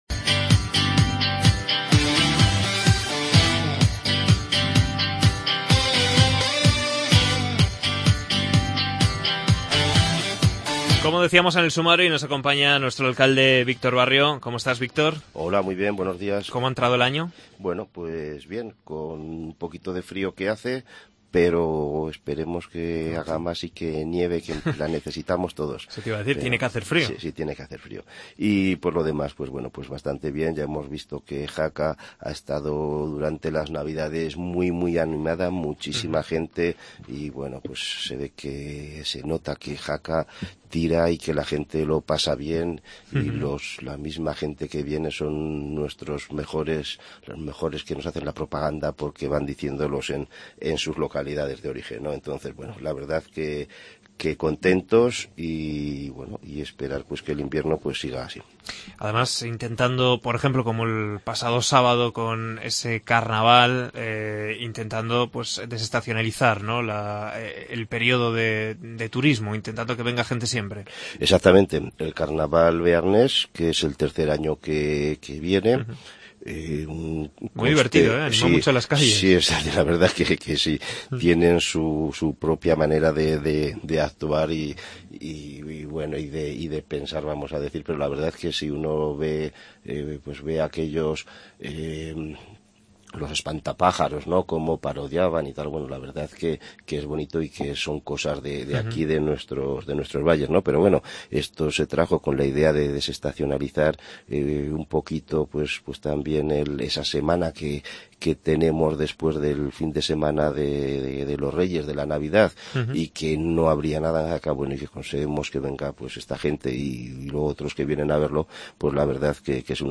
AUDIO: Entrevista al alcalde de Jaca Víctor Barrio